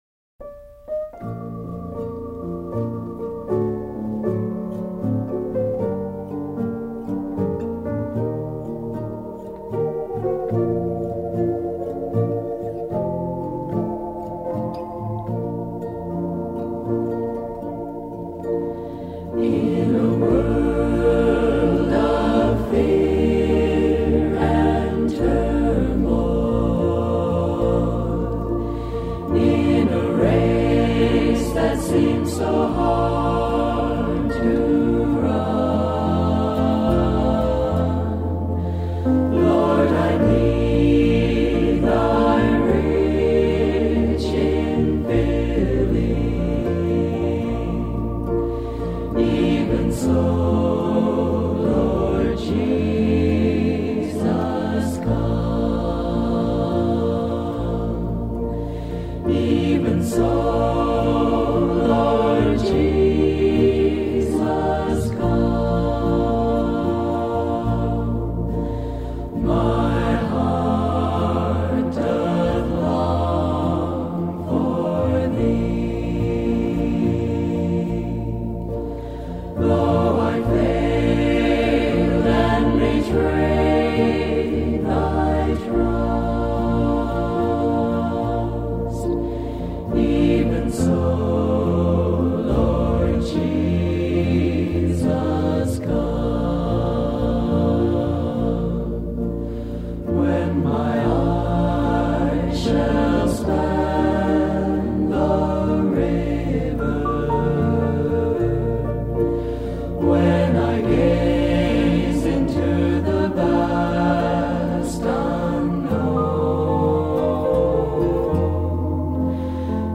No nosso hino de hoje